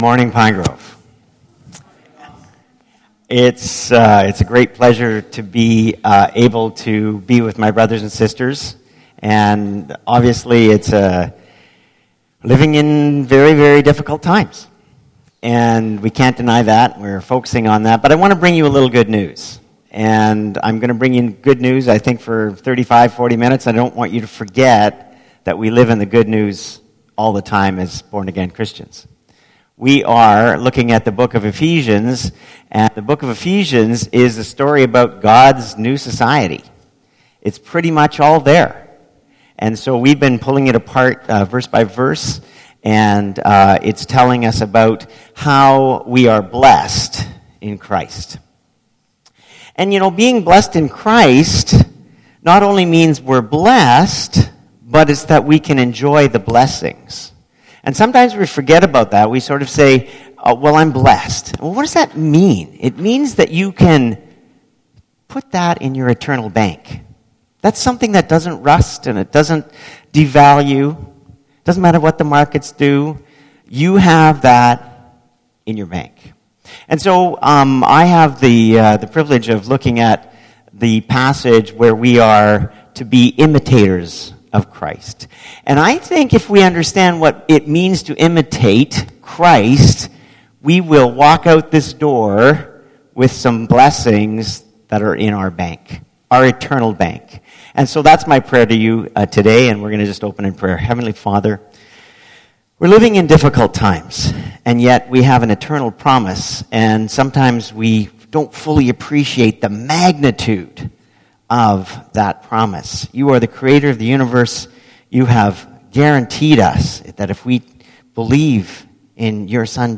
2020 Sermons